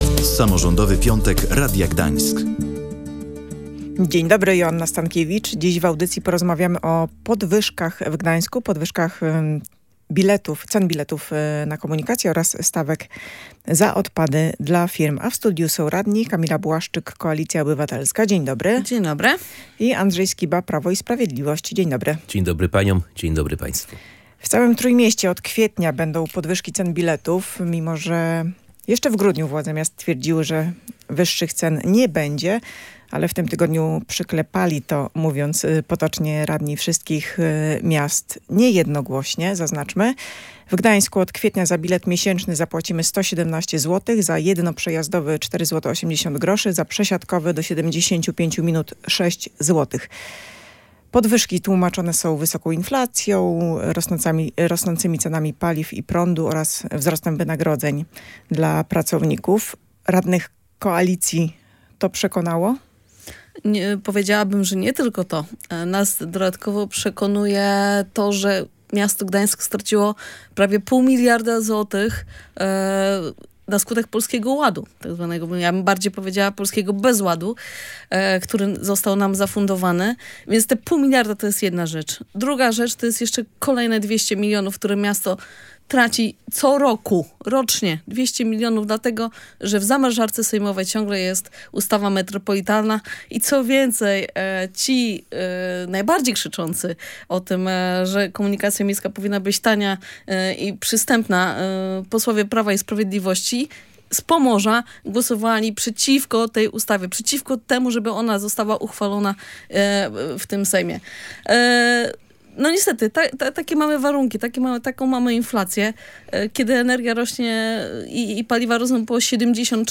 Prowadząca zapytała radnych, czy ich przekonuje taka argumentacja.